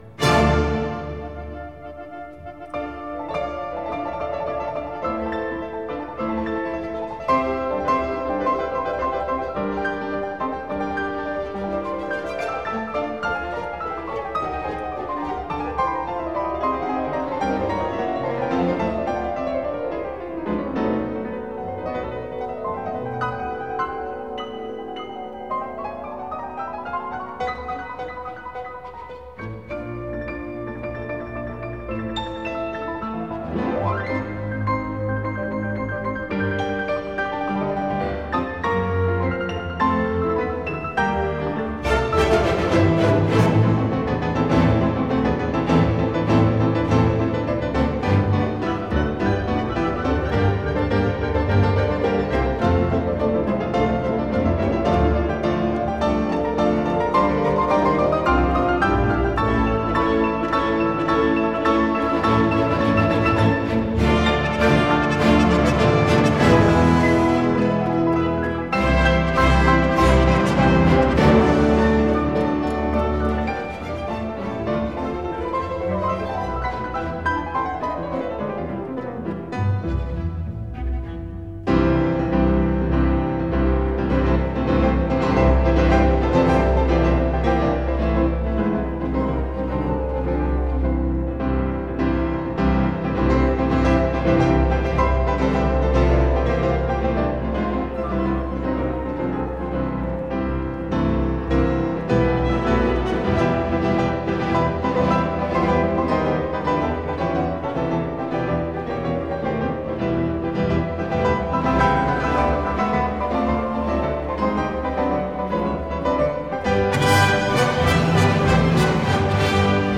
Download 06_Piano_Concerto_No._3_in_D_minor,_Op._30_-_III._Finale_-Alla_breve.mp3